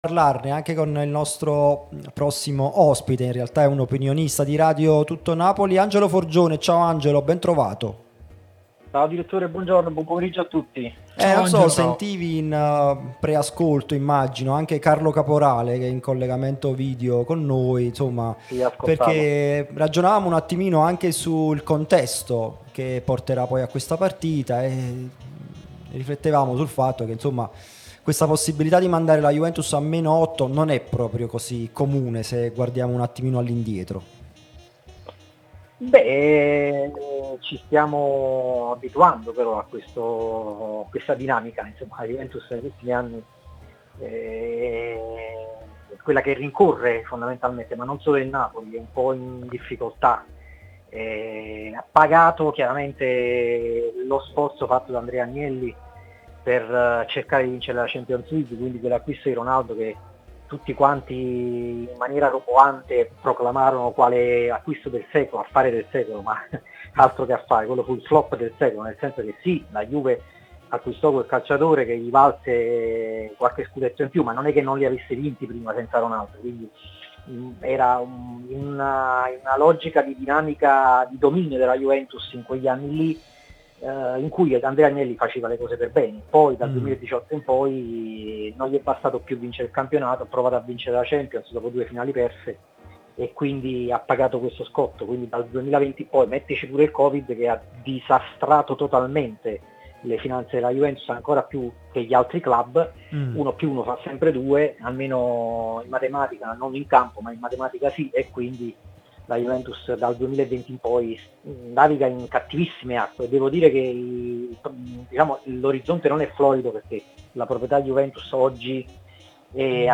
giornalista e scrittore , è intervenuto nel corso della trasmissione 'Napoli Talk' sulla nostra Radio Tutto Napoli